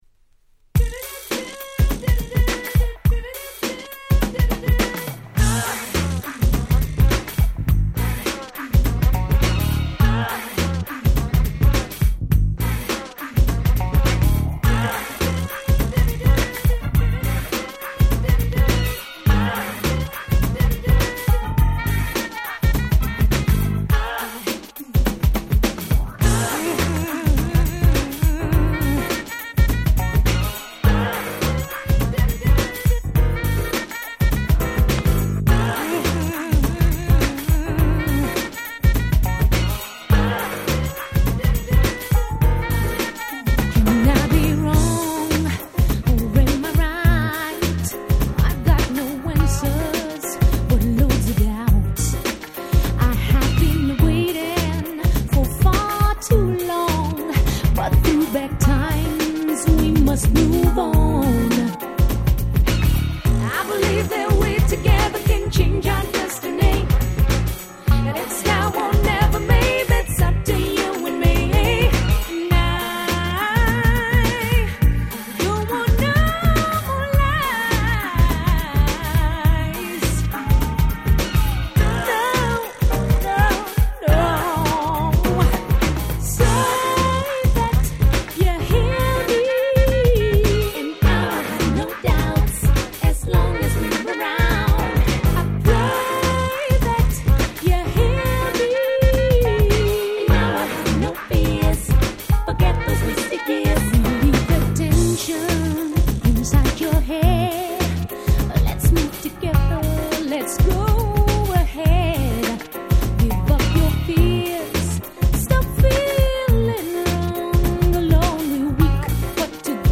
爽やかな歌、嫌みのないRap、美しいトラックが三位一体！